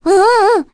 Requina-vox-Deny_kr.wav